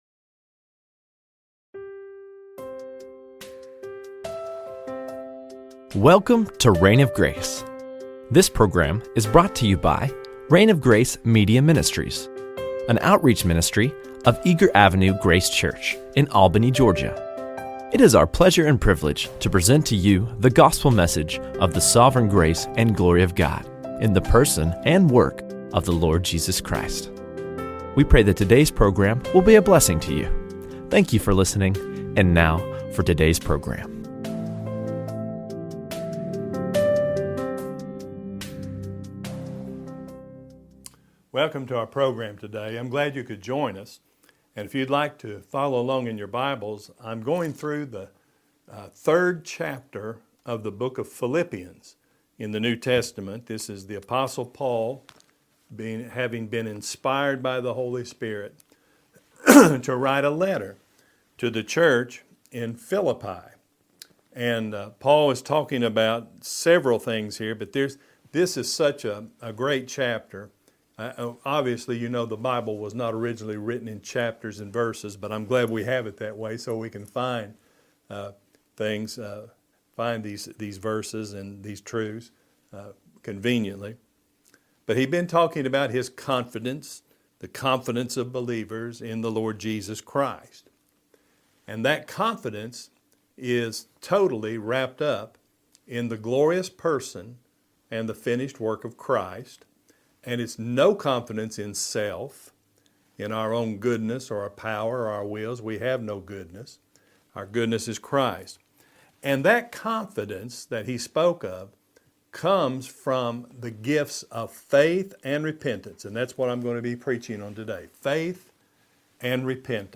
Faith and Repentance | SermonAudio Broadcaster is Live View the Live Stream Share this sermon Disabled by adblocker Copy URL Copied!